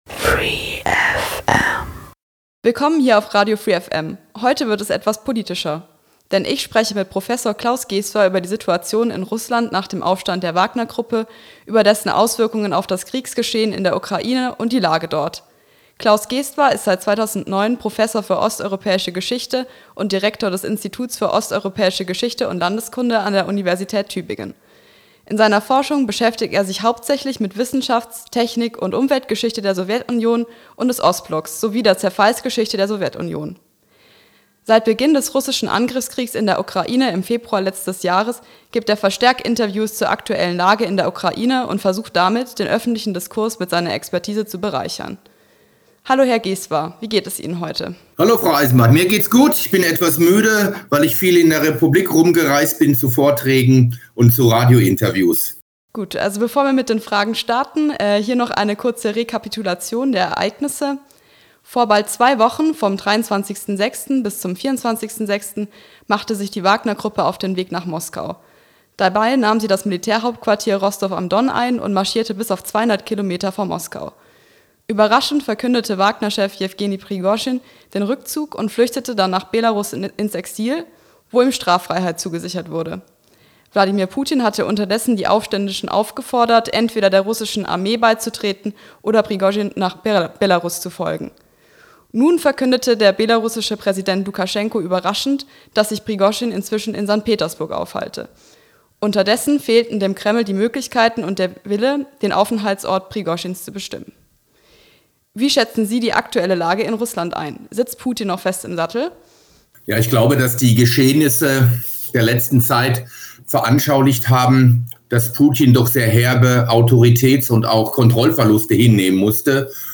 Ulmer Freiheit 14.07.2023 | 18:03 Interview mit PUBLIC DISPLAY OF AFFECTION Gestern war die Berliner Band PUBLIC DISPLAY OF AFFECTION im GOLD zu Gast.